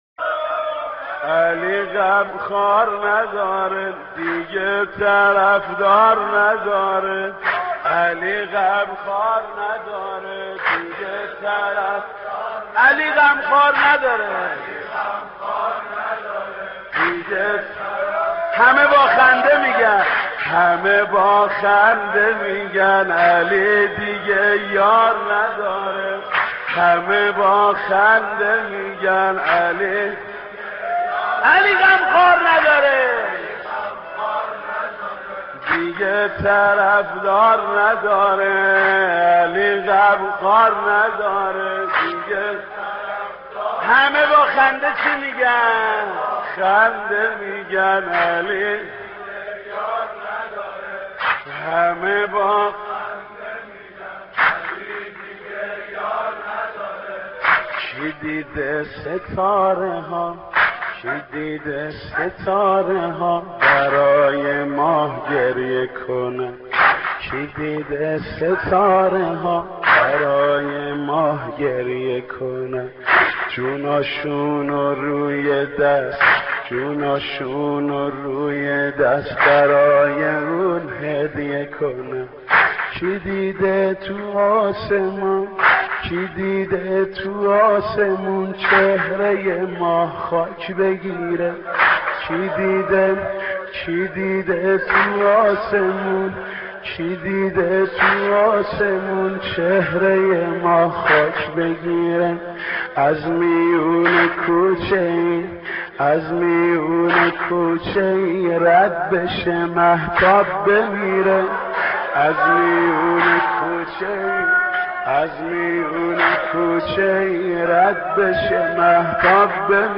مراسم نوحه‌خوانی در عزای شهادت بانوی خوبیها زهرا اطهر (س)